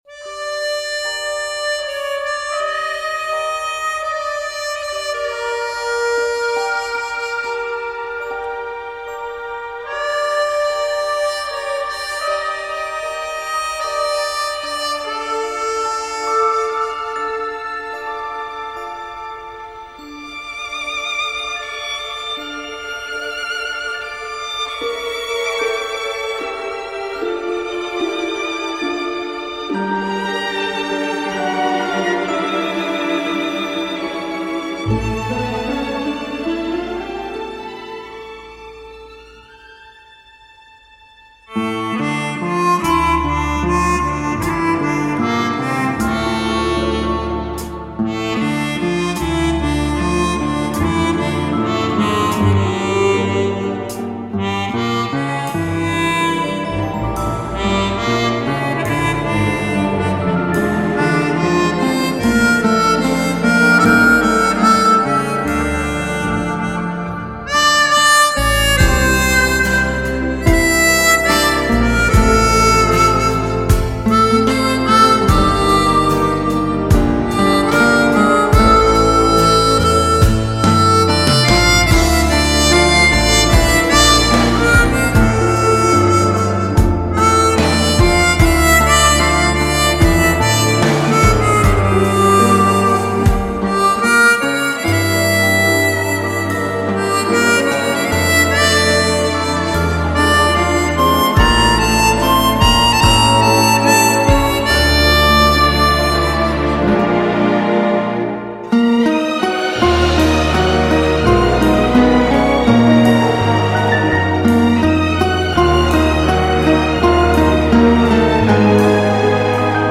هارمونیکا